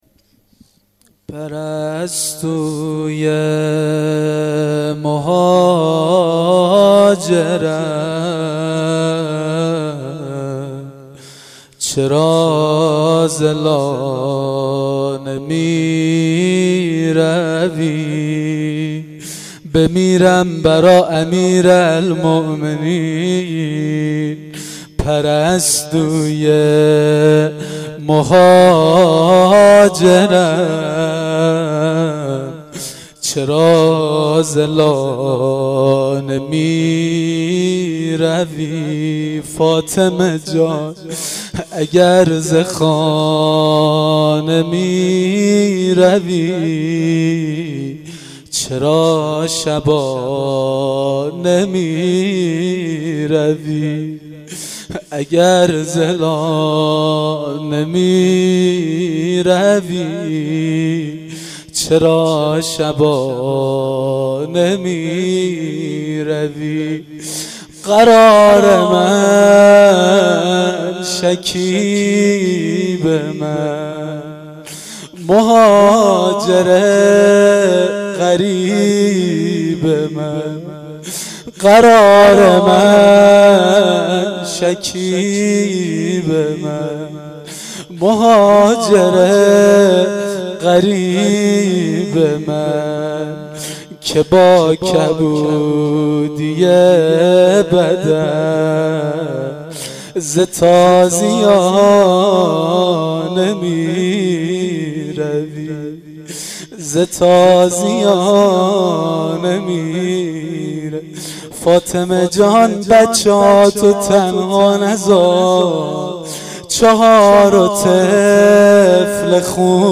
زمزمه شب دوم فاطمیه